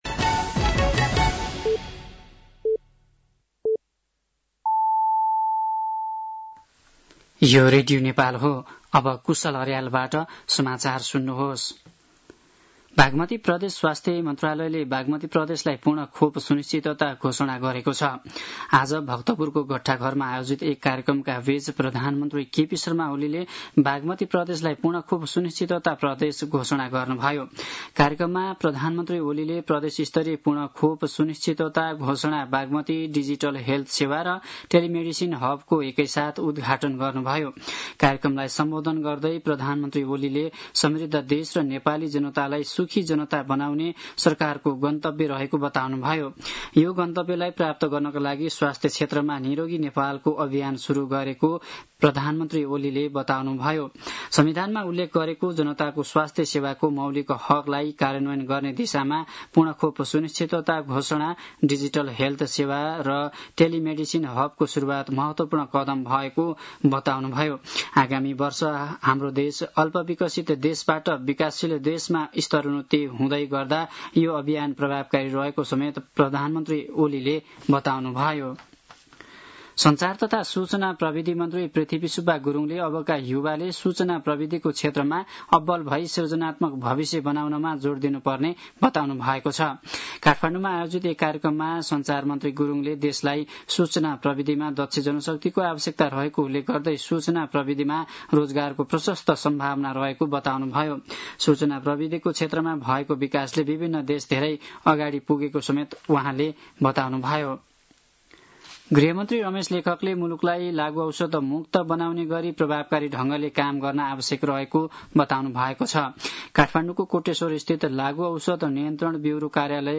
साँझ ५ बजेको नेपाली समाचार : १६ साउन , २०८२
5-pm-nepali-news-4-16.mp3